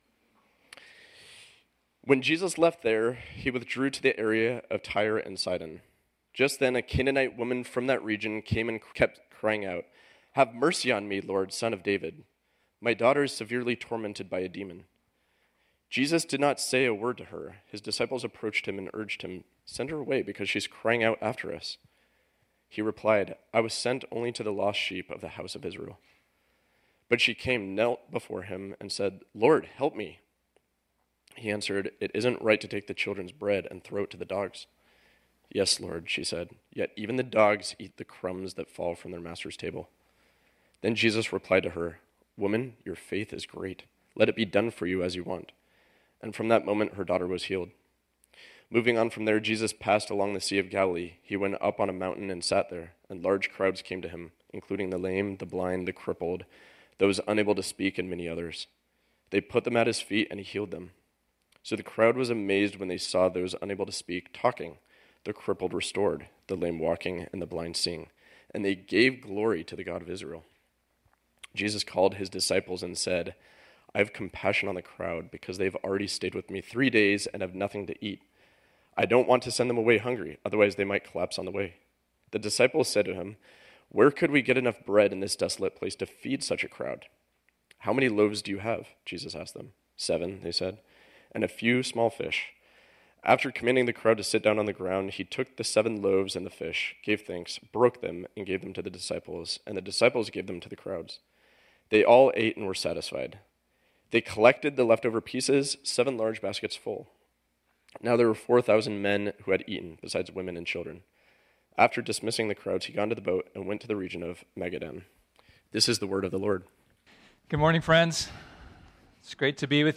” our sermon series on the Gospel of Matthew.